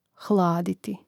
hláditi hladiti